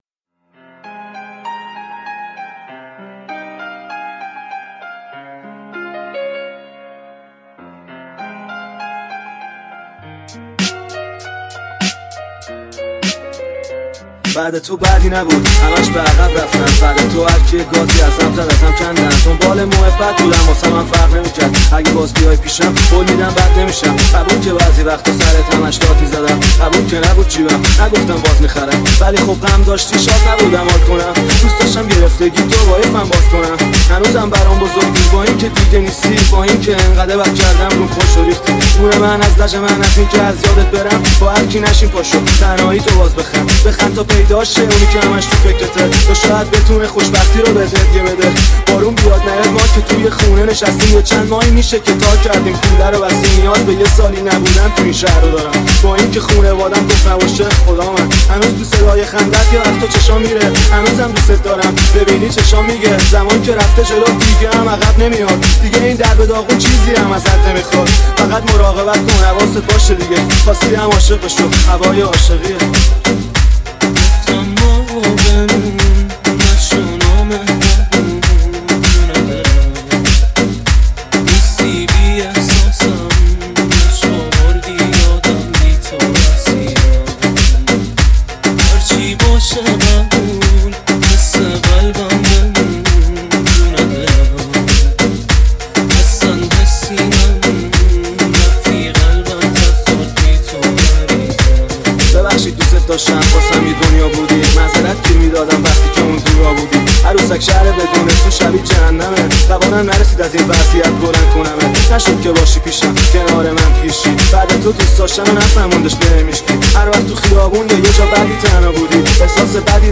تند بیس دار رپ تند بیس دار سیستمی